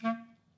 DCClar_stac_A#2_v2_rr1_sum.wav